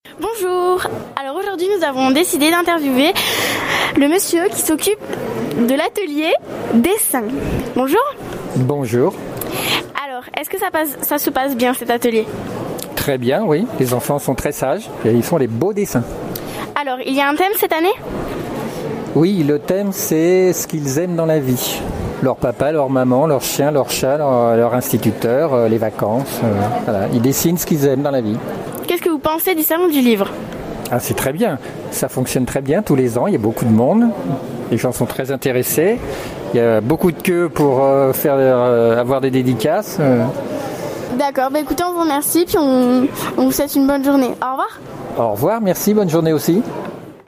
VOICI LES REPORTAGES
atelier-dessin.mp3